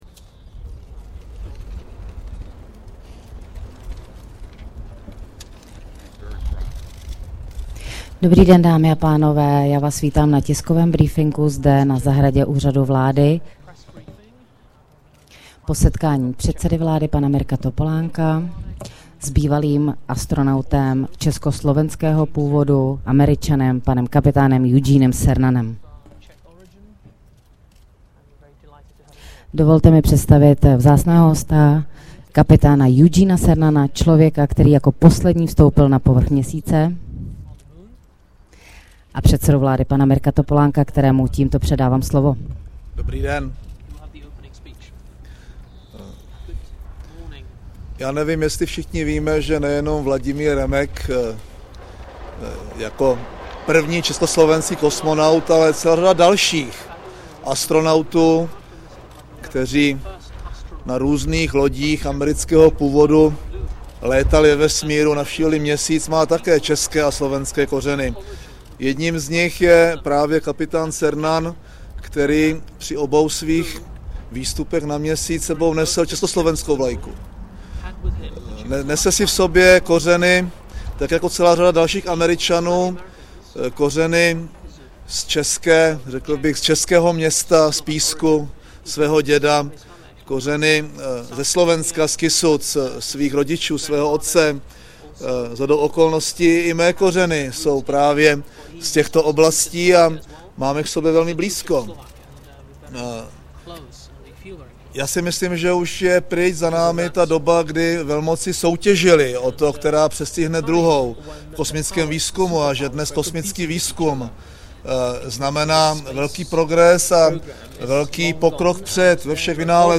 Tisková konference po setkání premiéra Mirka Topolánka s americkým astronautem Eugene A. CERNANEM 19.června 2008